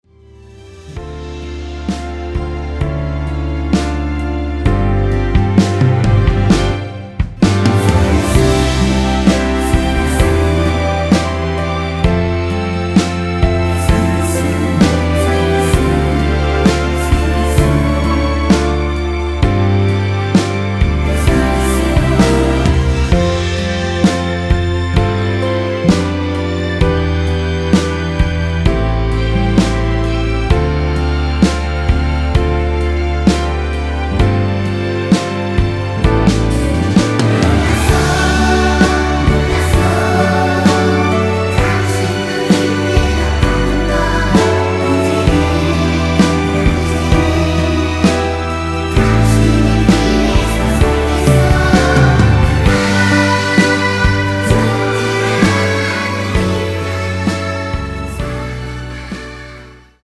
원키에서(+3)올린 코러스 포함된 MR입니다.(미리듣기 확인)
앞부분30초, 뒷부분30초씩 편집해서 올려 드리고 있습니다.